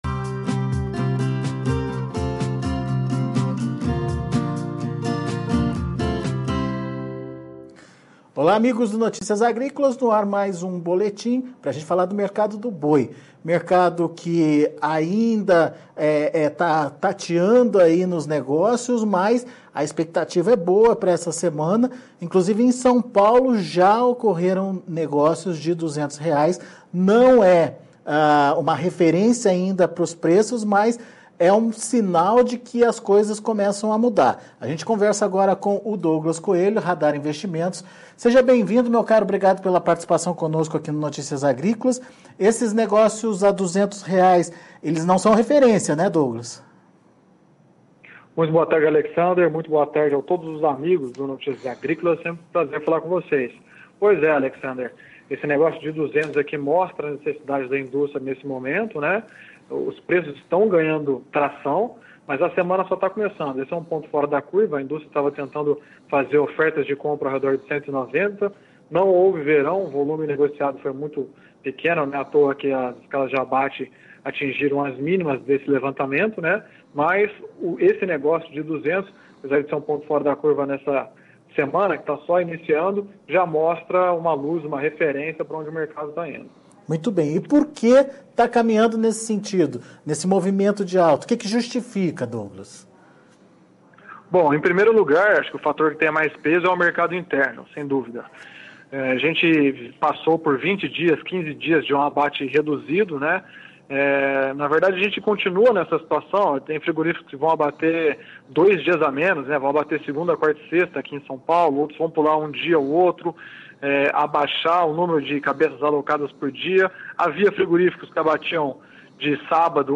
Mercado do boi gordo - Entrevista